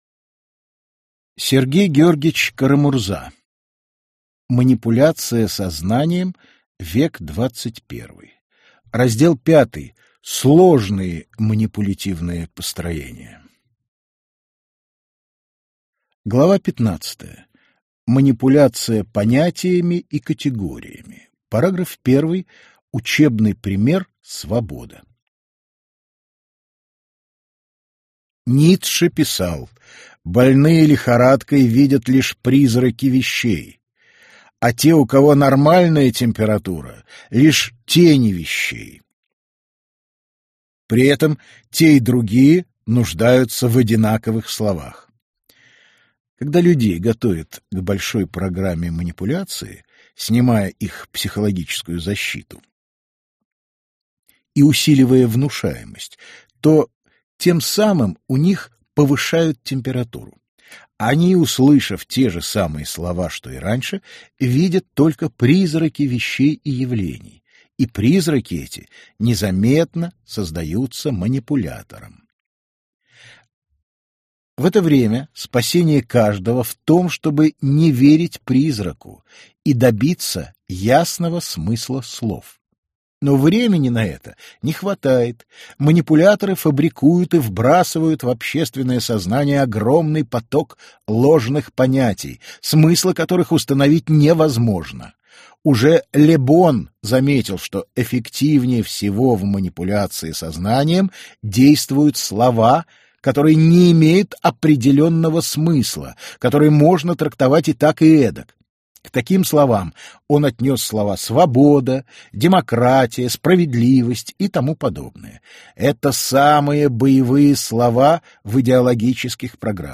Аудиокнига Манипуляция сознанием. Век XXI. Раздел V. Сложные манипулятивные построения | Библиотека аудиокниг